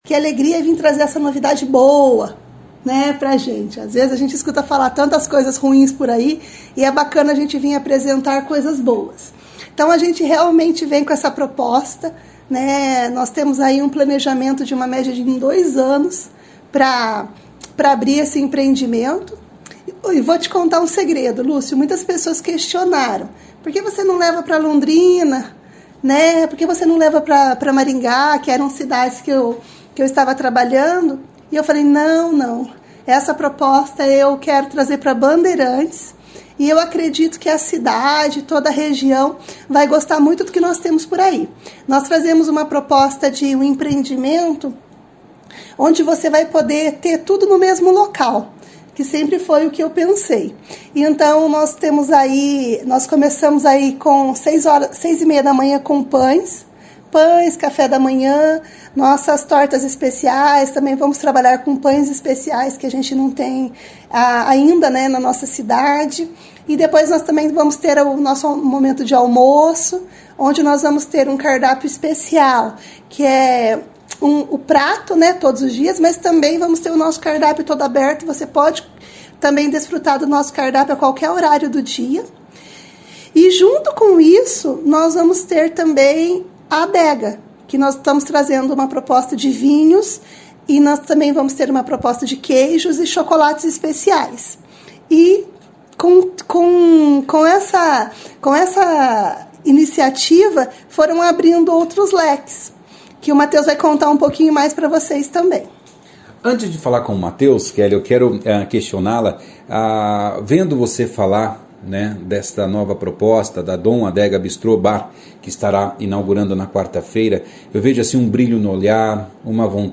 Em entrevista ao jornal Operação Cidade